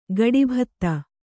♪ gaḍibhtta